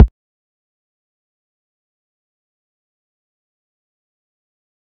Kick (I Got these).wav